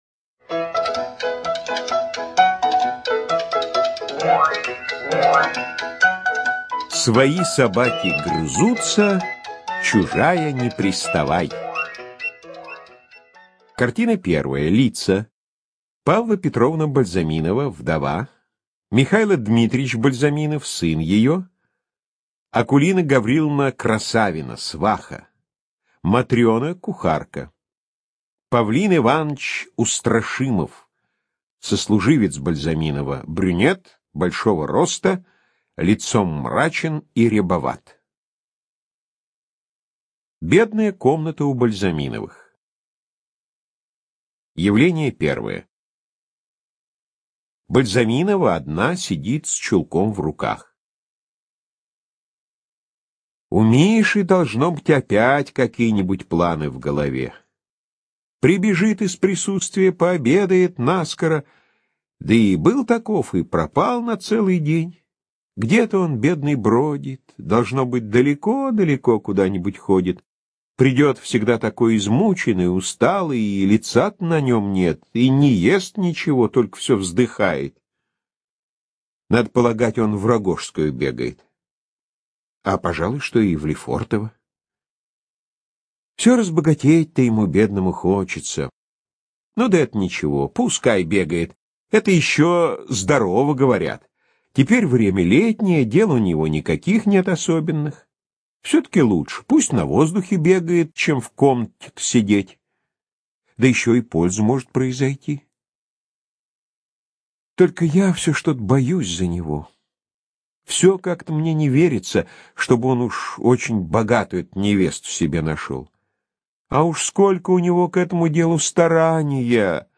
ЖанрДраматургия
Студия звукозаписиАрдис